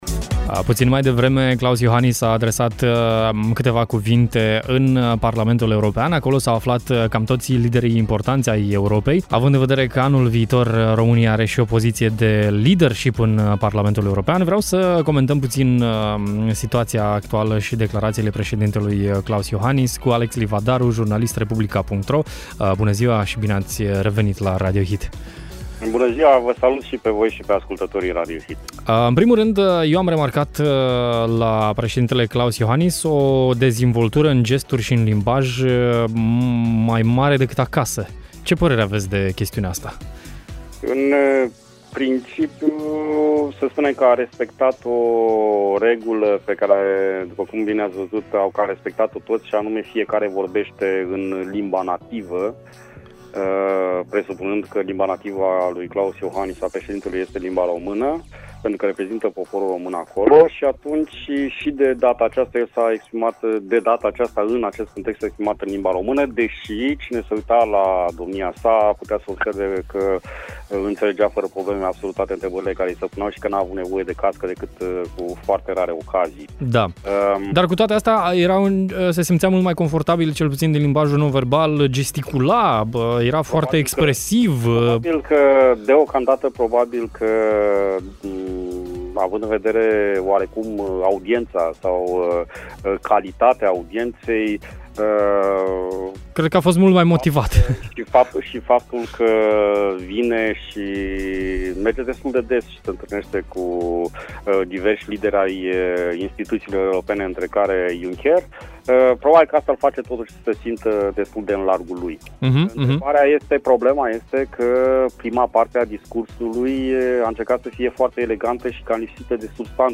Printre altele, președintele a militat pentru unitate și coeziune și a respins ideea unei Europe cu mai multe viteze. Declarațiile președintelui au fost comentate la Radio Hit